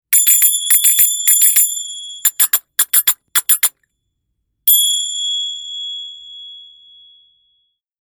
High pitch and clear tone, approximately 2-1/2" in diameter, and weigh 188 grams (6.64 oz.).